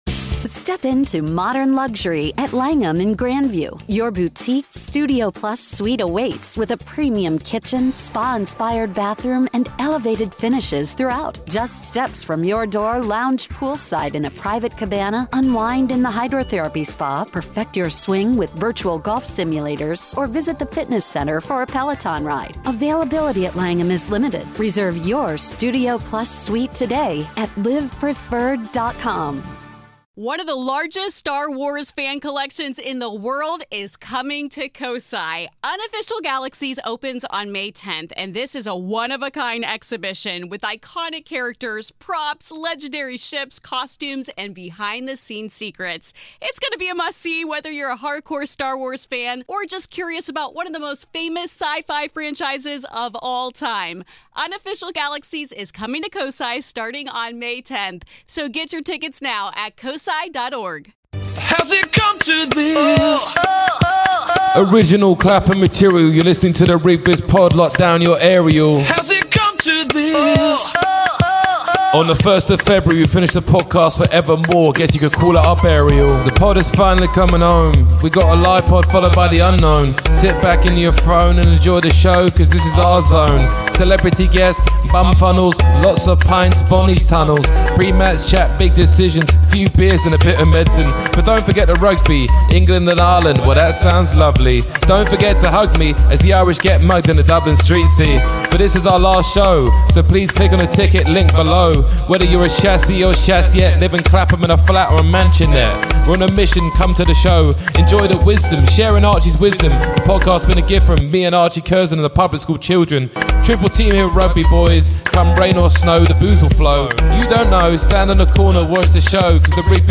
Conrad 'Snakey' Smith - The Greatest Centre Of All Time Joins The Pod For An Exclusive Interview!